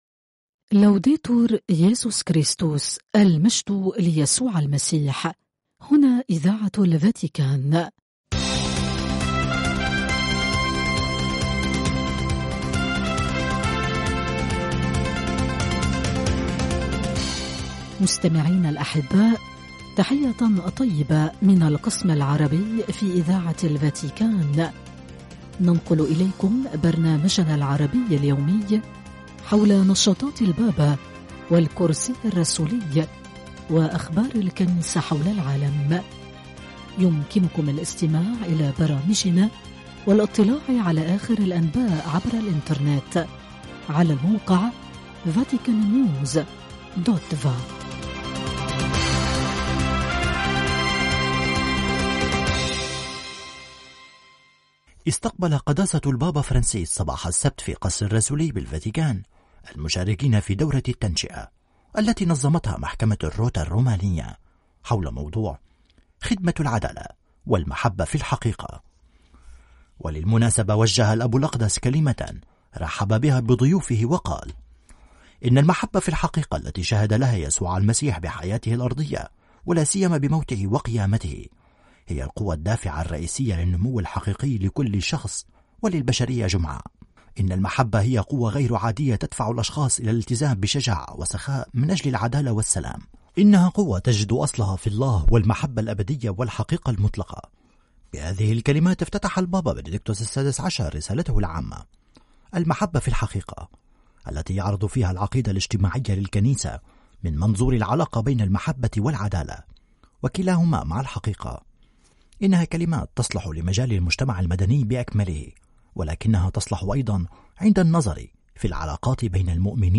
أخبار